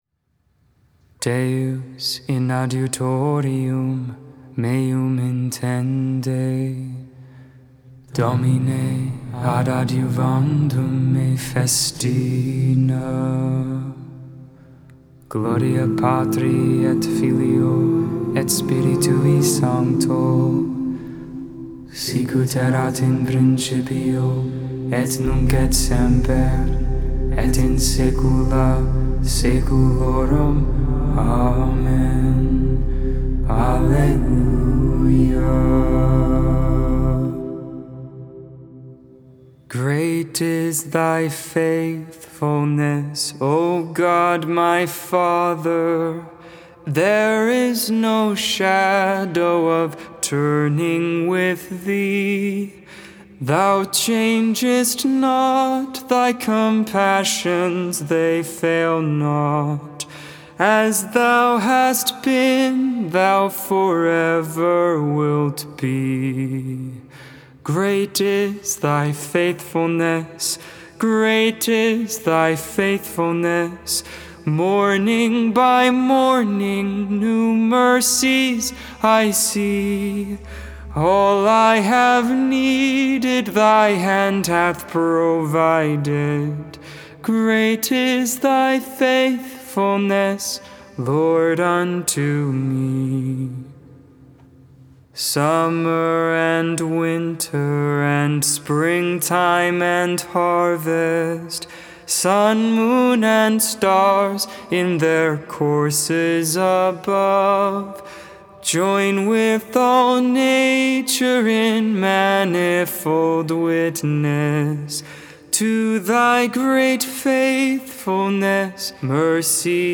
The Liturgy of the Hours: Sing the Hours 5.25.22 Ascension Vespers I, Wednesday Evening Prayer May 25 2022 | 00:17:04 1x 00:00 / 00:17:04 Subscribe Share Spotify RSS Feed Share Link Embed